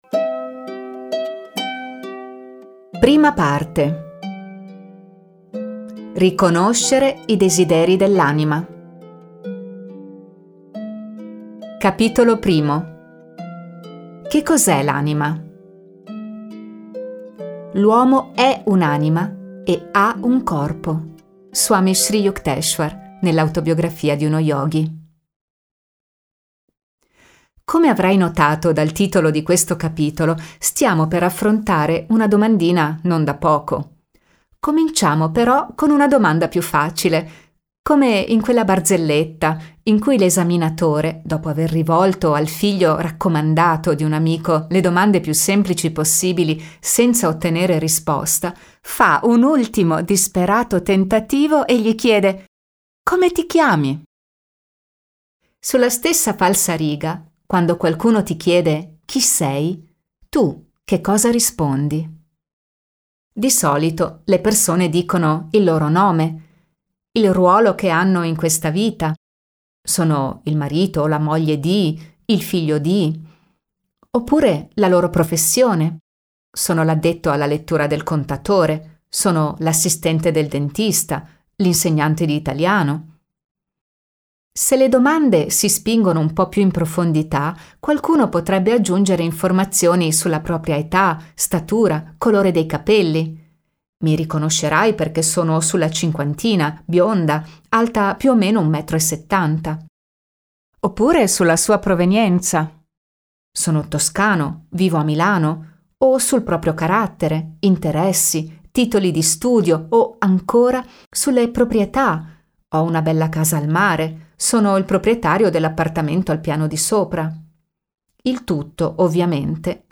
Un audiolibro completo, pratico e potente… che funziona!